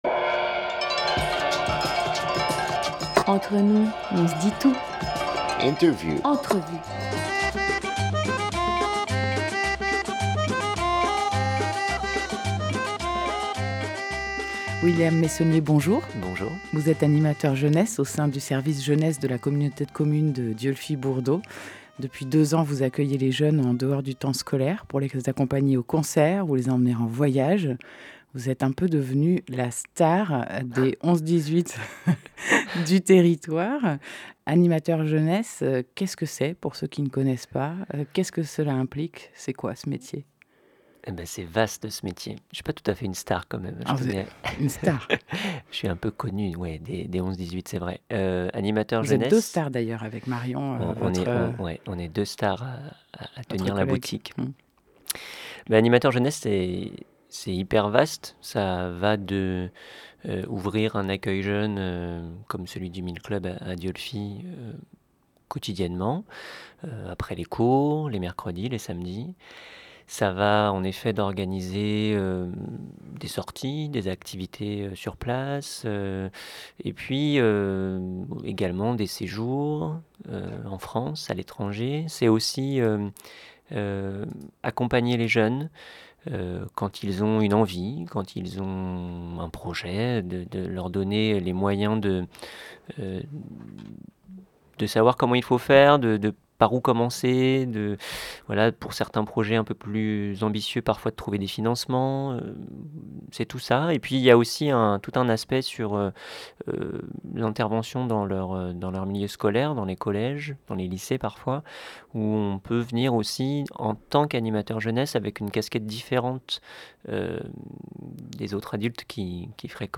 10 février 2018 12:00 | Interview